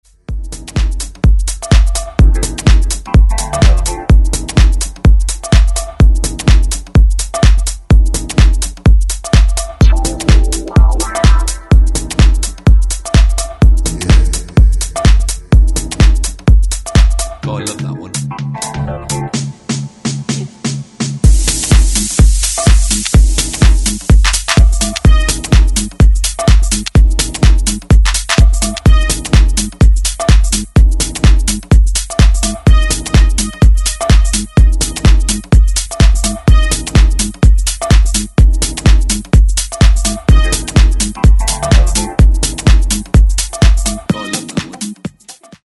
Styl: Techno, Minimal